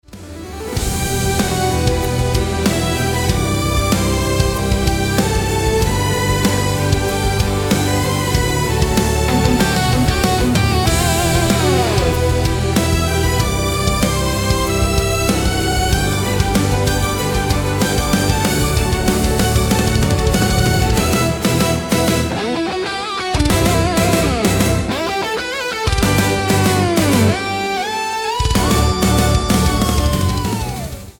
Cinematic Orchestral